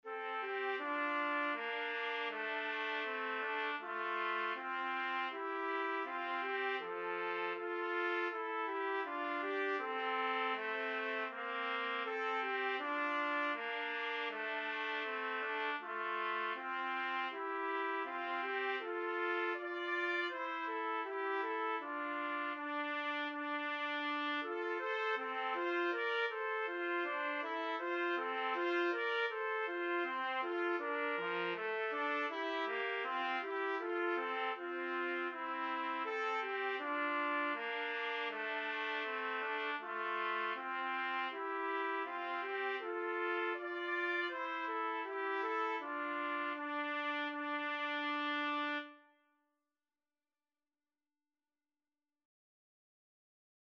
Trumpet 1Trumpet 2
Andante Espressivo = c. 80
4/4 (View more 4/4 Music)